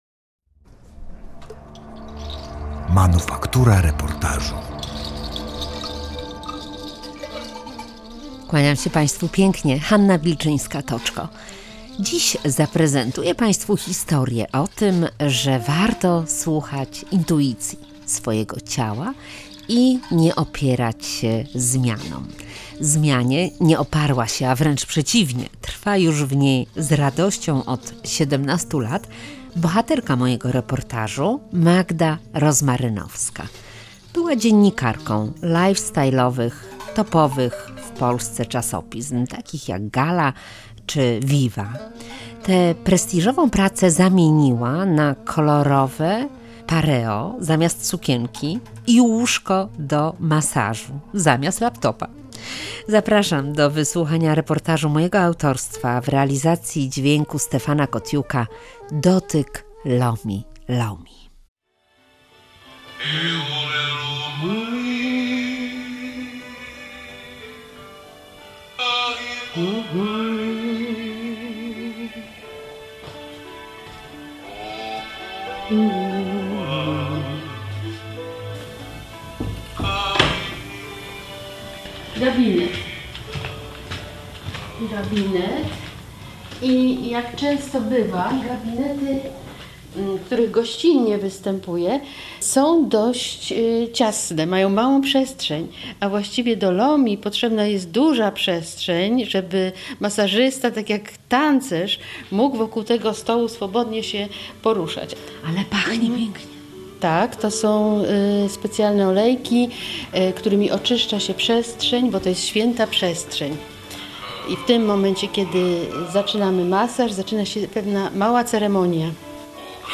Reportaż "Dotyk lomi lomi".